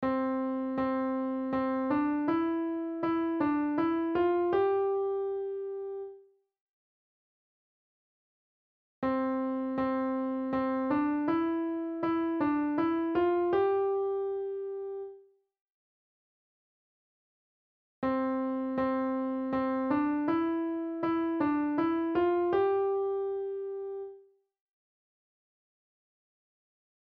On the piano, play Row, Row Row Your Boat